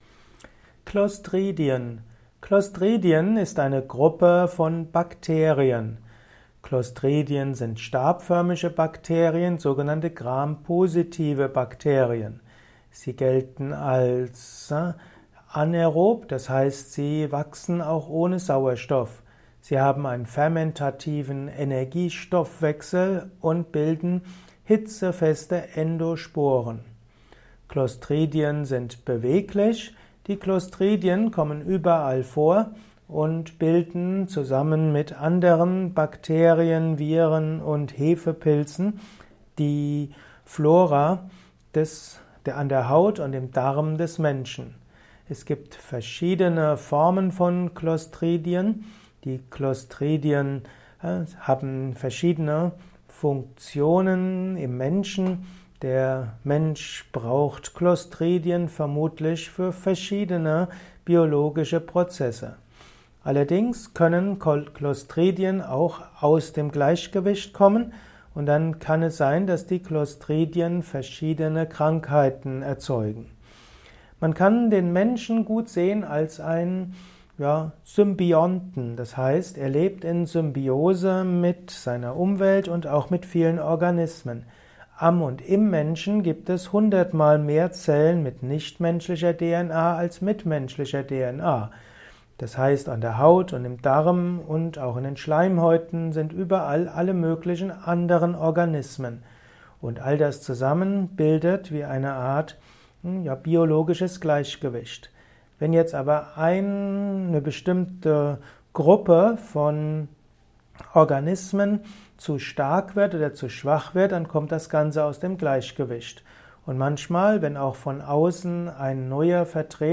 Kurzvortrag